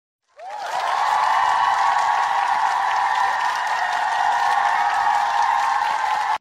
Crowd Cheer Yes Finaly Theyre Married Botão de Som